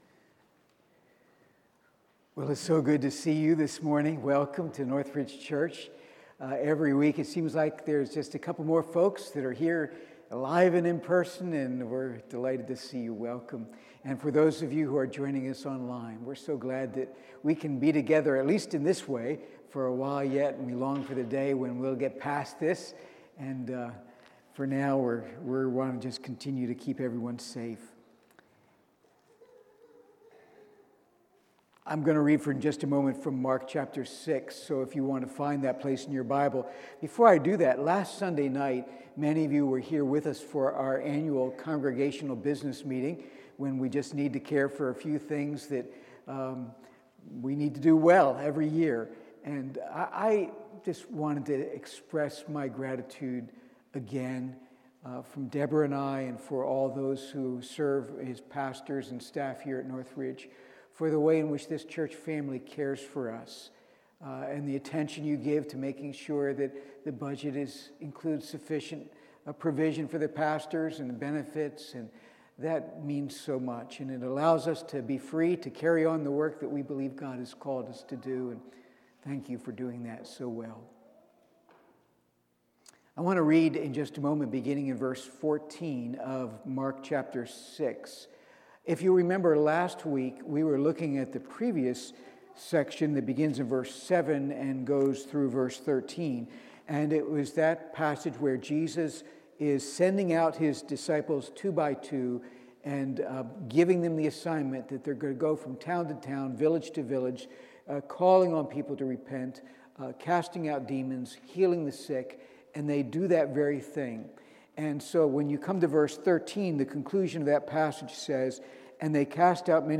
Sermons | North Ridge Church